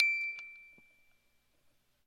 音乐盒发条
描述：原本的录音有很大的问题，因为它相当嘈杂，以及不正确居中。我处理以后这声音噪音较小，声音较大，并且居中。
标签： 混音 音乐盒 向上
声道单声道